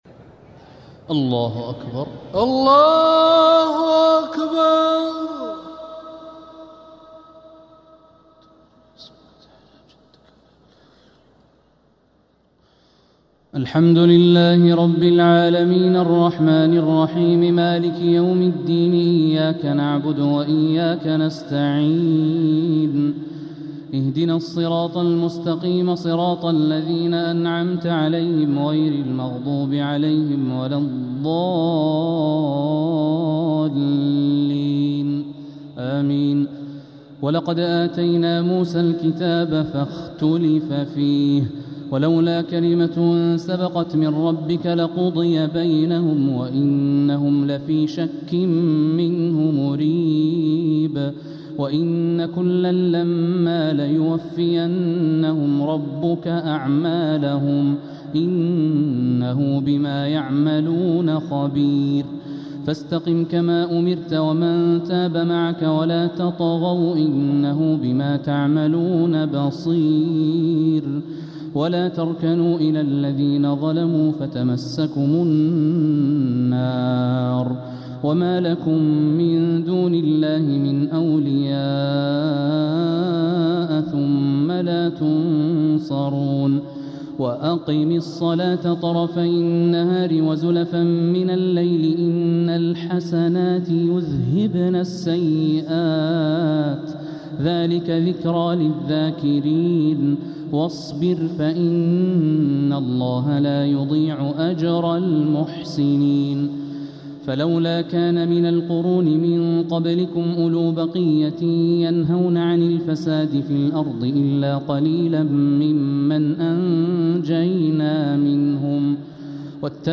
تراويح ليلة 16 رمضان 1447هـ من سورتي هود (110-123) و يوسف (1-35) | Taraweeh 16th niqht Ramadan 1447H Surat Hud and Yusuf > تراويح الحرم المكي عام 1447 🕋 > التراويح - تلاوات الحرمين